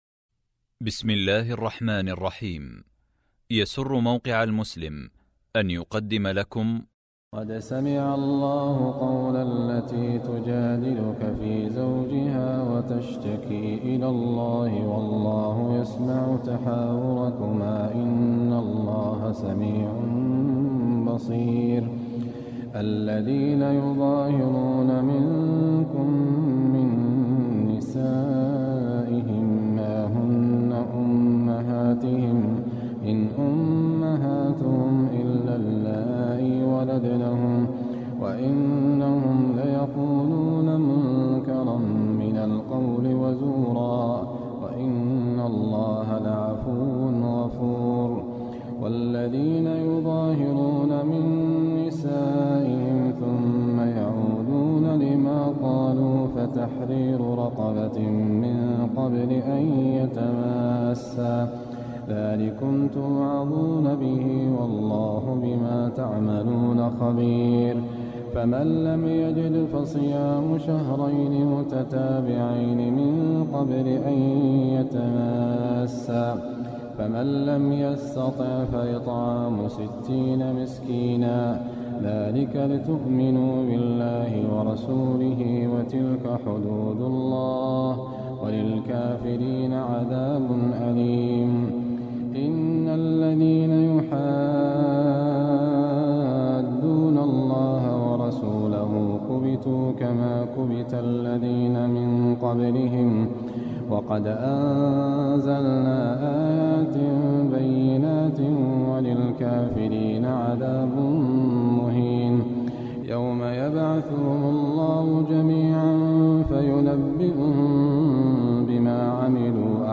جزء المجادلة من تراويح عام 1430 هـ | موقع المسلم